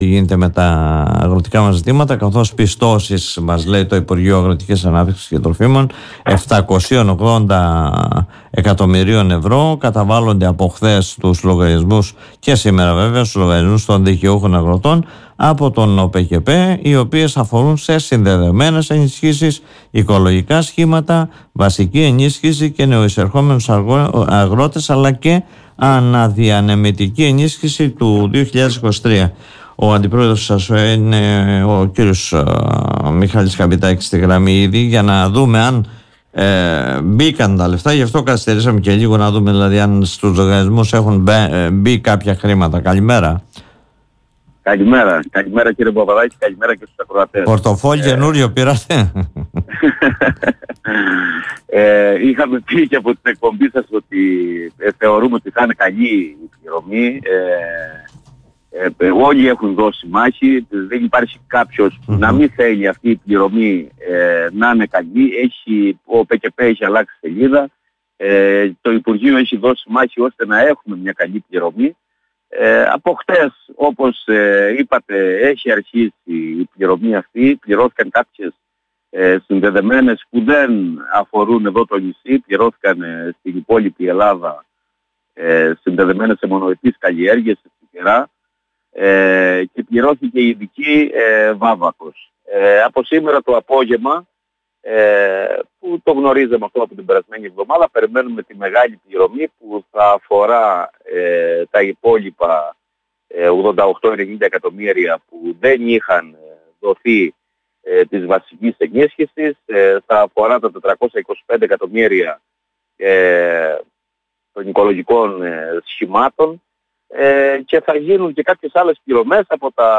Το θέμα σχολίασε μιλώντας στην εκπομπή “Δημοσίως”